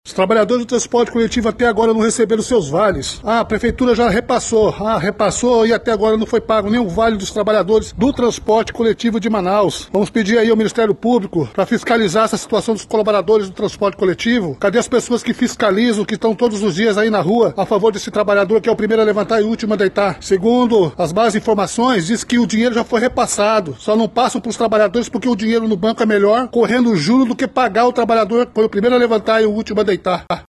Uma das lideranças dos rodoviários disse que os valores já foram repassados pela Prefeitura de Manaus, mas as empresas não pagam os trabalhadores.